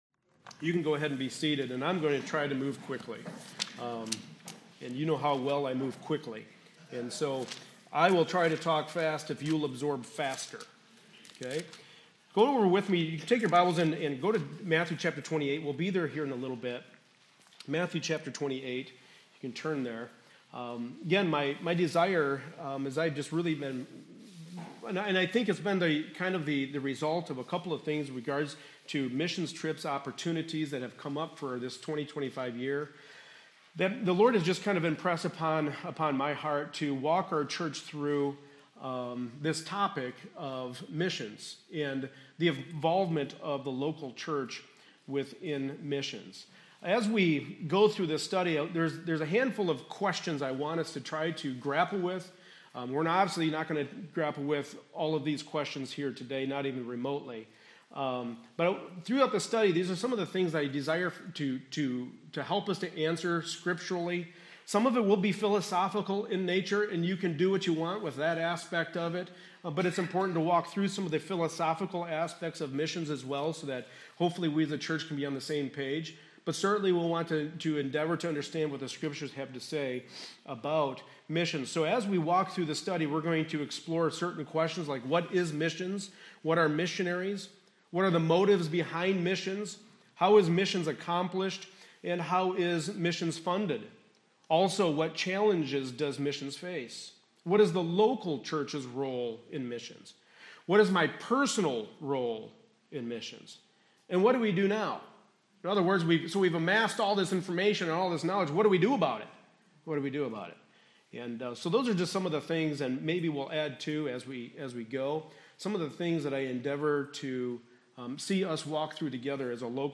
Passage: Matthew 28:18-20; Acts 13:1-4 Service Type: Sunday Morning Service